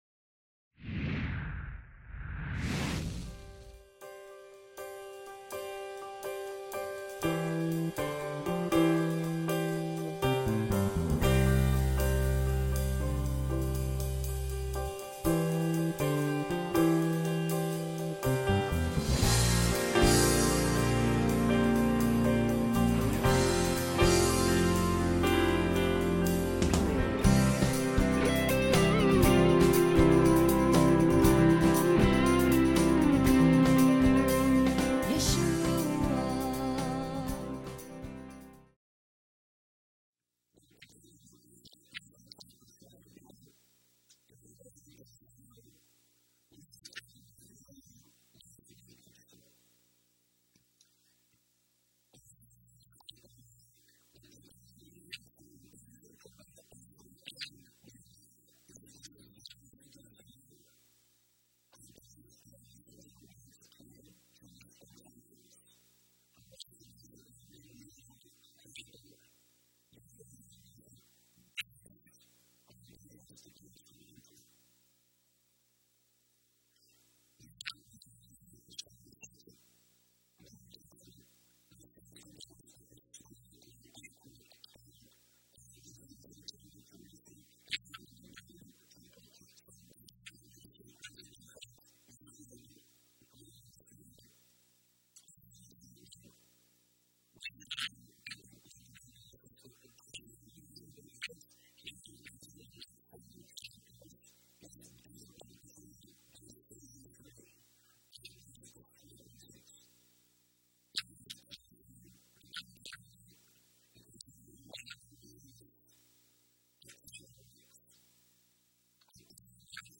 Lesson 6 Ch3 Ch4 - Torah Class